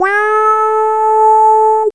Index of /90_sSampleCDs/Vince_Clarke_Lucky_Bastard/SYNTHS/MULTIS